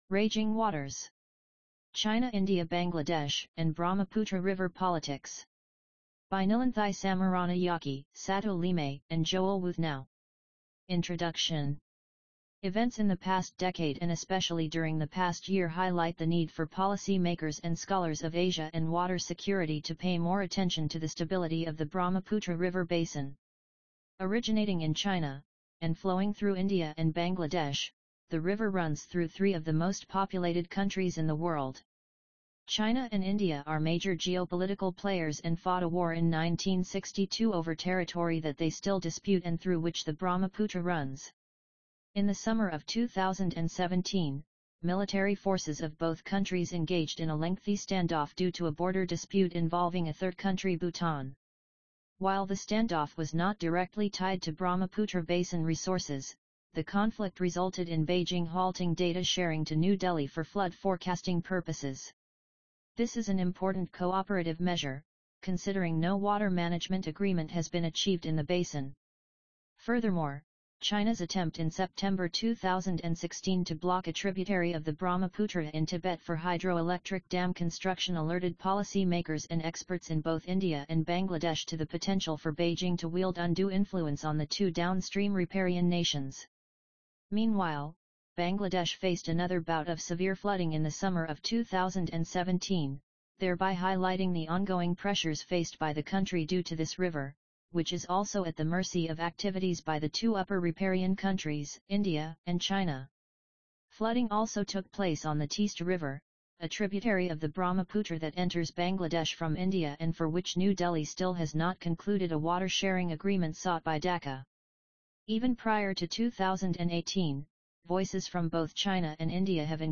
RagingWaters_AUDIOBOOK.mp3